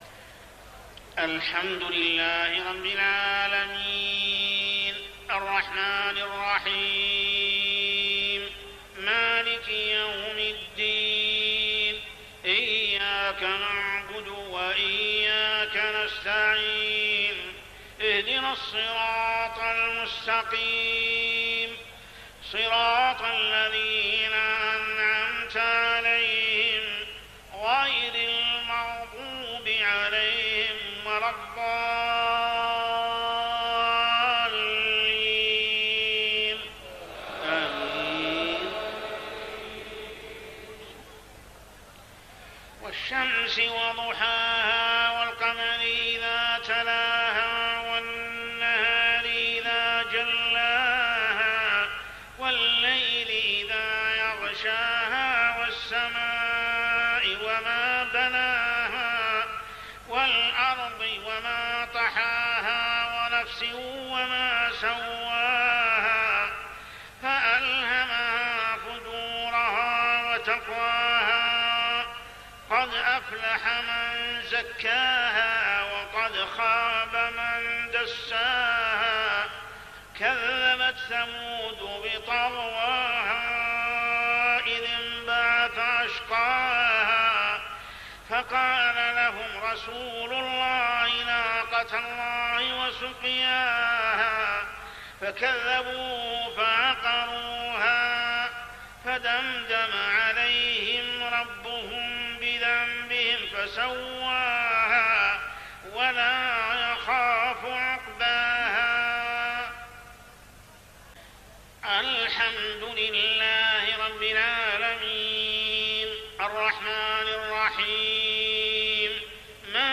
عشائيات شهر رمضان 1423هـ سورتي الشمس و الزلزلة كاملة | Isha prayer Surah Ash-Shams and Az-Zalzalah > 1423 🕋 > الفروض - تلاوات الحرمين